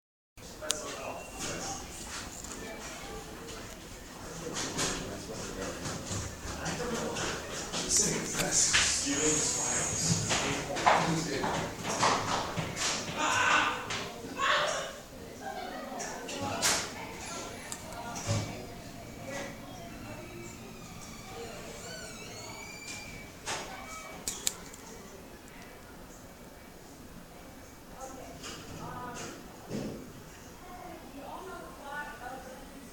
Identify the location of the clip – I recorded this clip in the lobby of the Spiegel just before the beginning of pie night. There was a large group standing around waiting to be let in and someone bumped the lights and made creepy noises. Identify the sounds heard in the clip – In this clip one can hear the large amount of activity and anticipation that comes in the minutes before a Cabaret night begins in the Spiegel. It begins with a smattering of conversations and yelling across the room and then one can hear the moment in which the lights go out and one student begins to make a rather odd, non humanlike noise that cuts through the room and causes a few people to scream. As people begin to figure out what is going they begin to laugh.
hallway.mp3